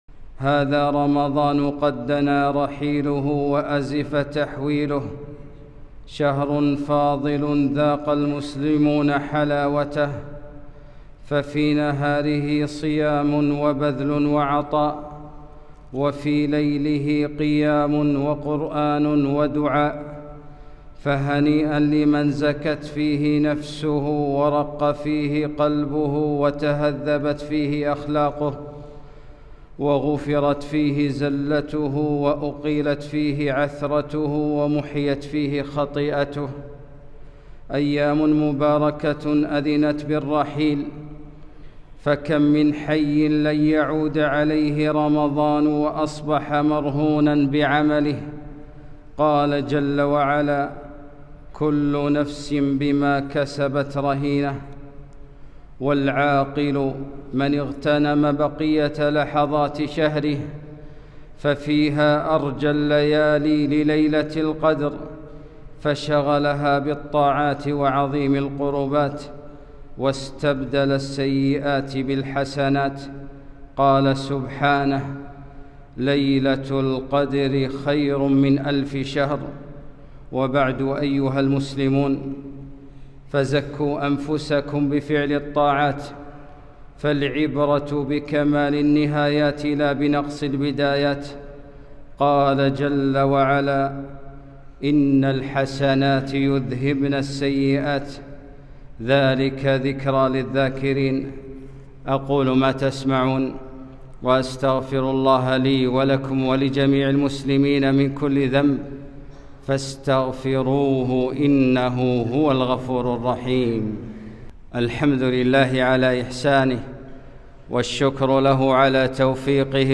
خطبة - كمال النهايات